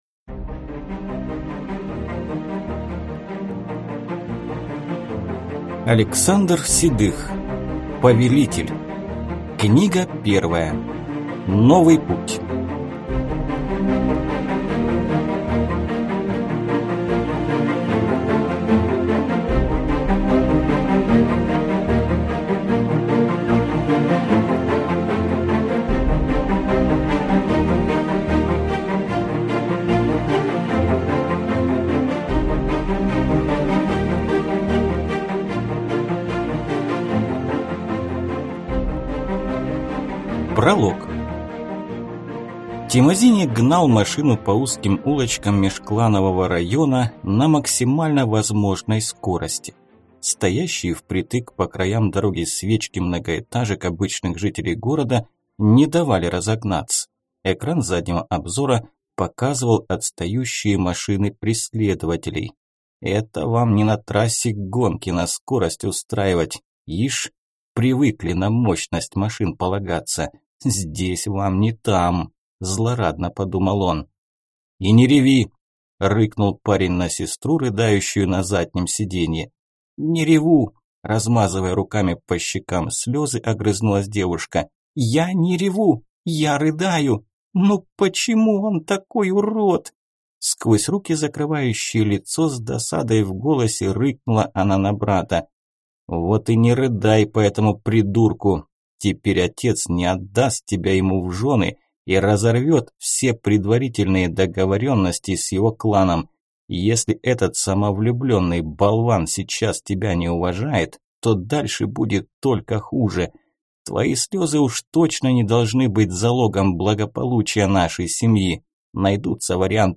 Аудиокнига Повелитель. Книга 1. Новый путь | Библиотека аудиокниг